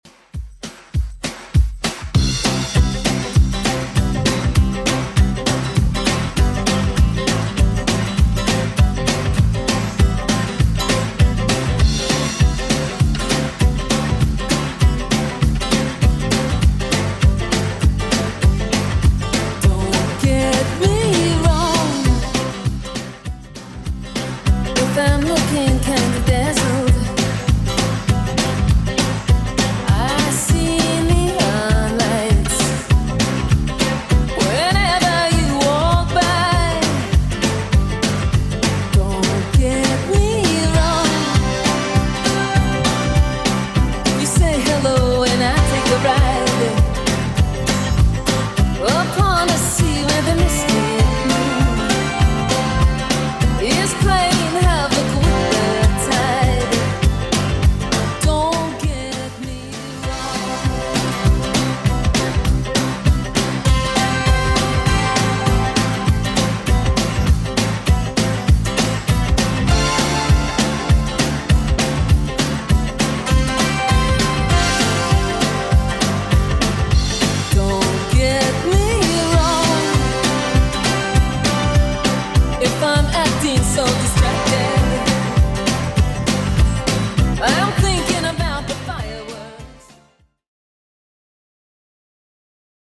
BPM: 199 Time